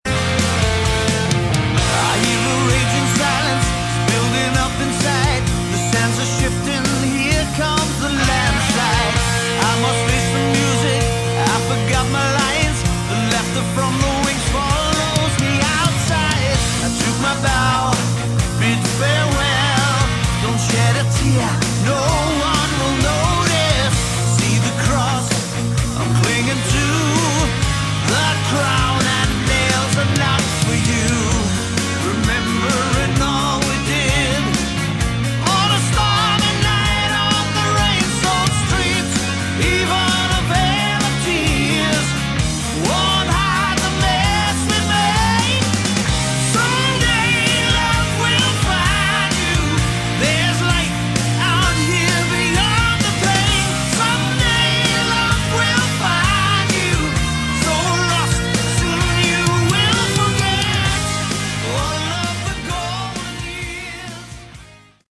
Category: Hard Rock
Vocals
Guitars